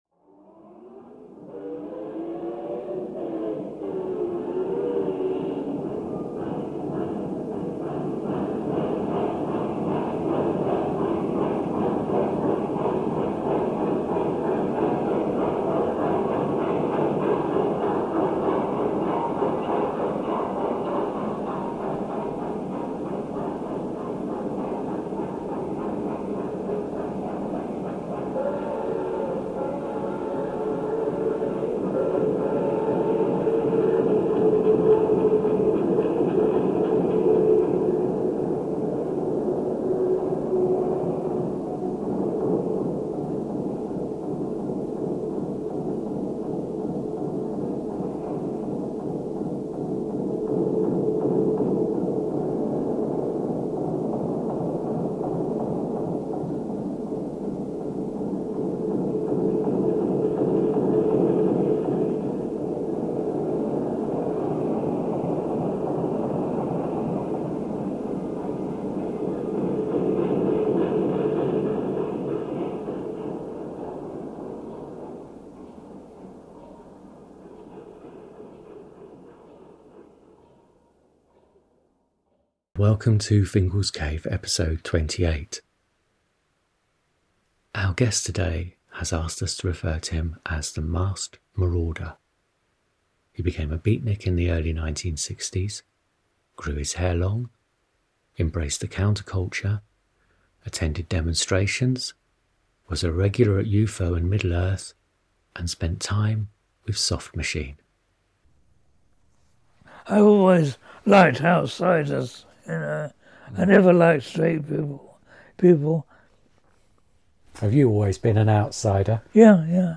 Recorded at his home on a busy London street, the conversation is rich with atmosphere, quiet pauses, and thoughtful insights. As always, Fingal’s Cave favours authentic conversations over formal interviews.